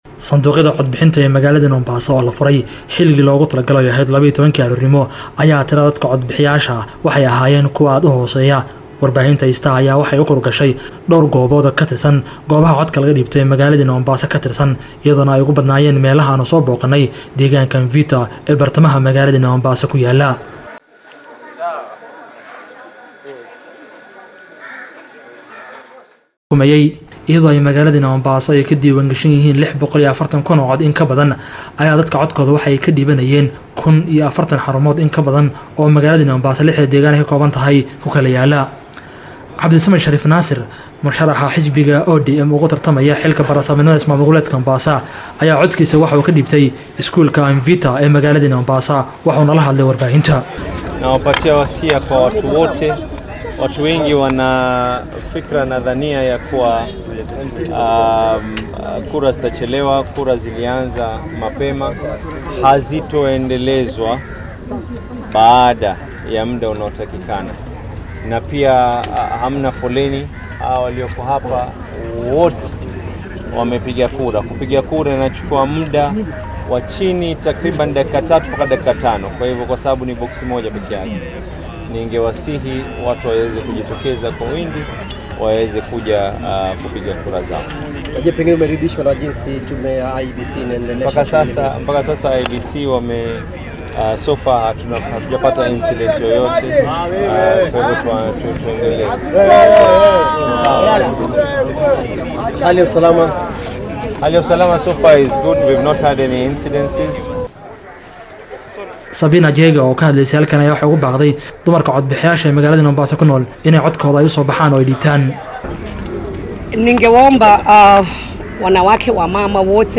Tirada codbixiyayaasha ilaa iyo hadda u soo baxay inay u codeeyaan kursiga guddoomiyaha ismaamulka Mombasa ayaa aad u yar, sida uu ku soo warramaya wakiil